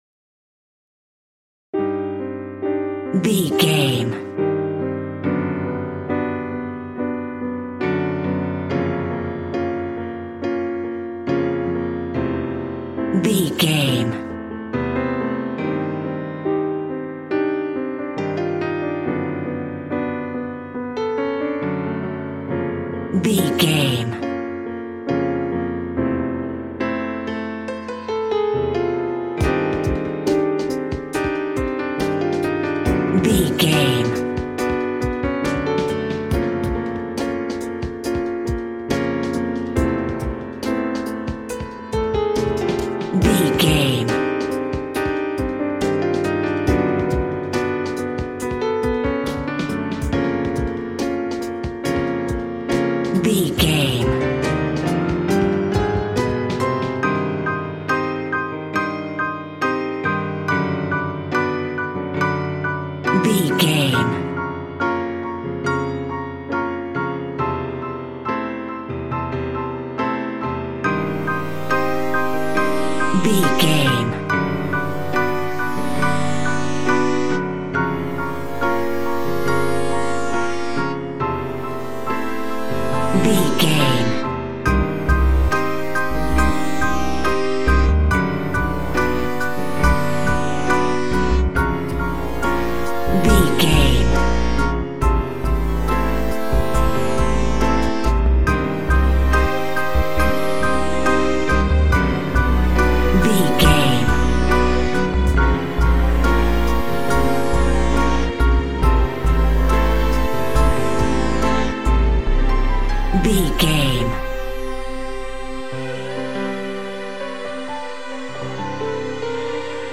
Horror Music Cue
In-crescendo
Aeolian/Minor
Slow
scary
tension
ominous
disturbing
haunting
eerie
piano
synthesiser
ambience
pads
eletronic